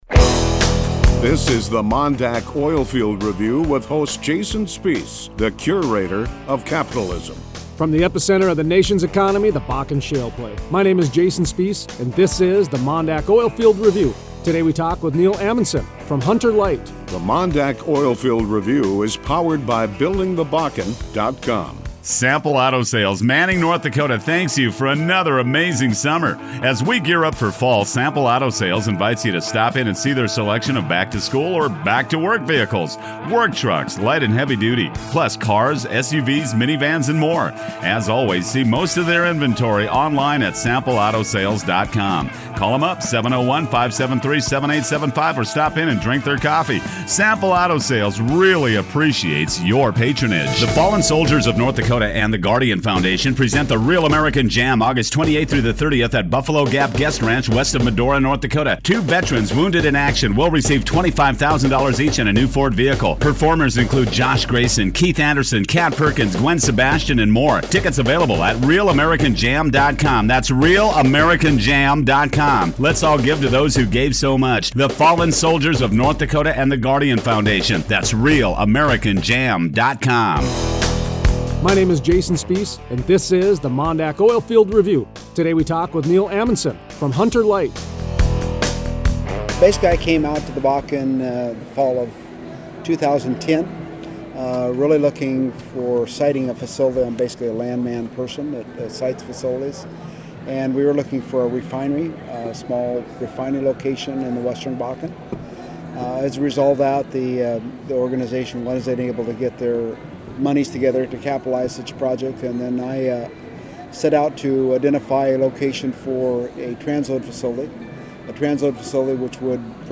Thursday 8/6 Interview